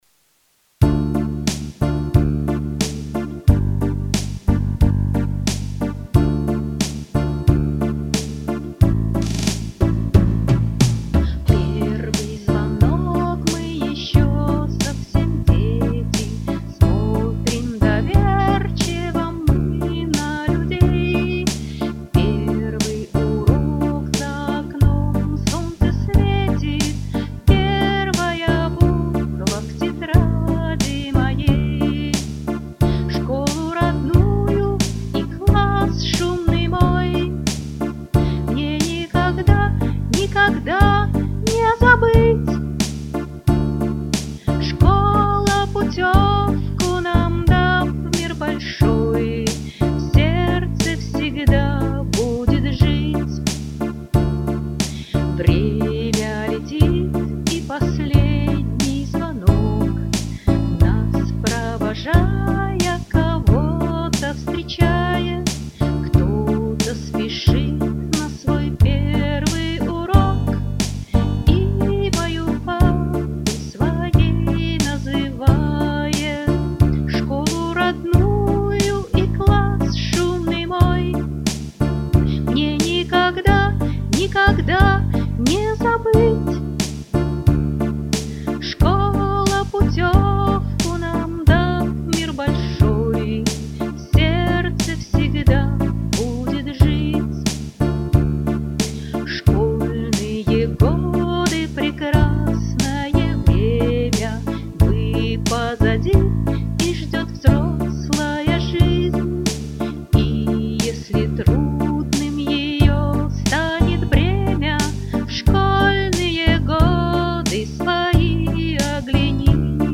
Романтическая музыка